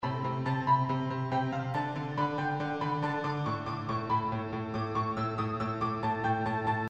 爵士乐：120 Bpm Rnb钢琴
Tag: 120 bpm RnB Loops Piano Loops 1.35 MB wav Key : Unknown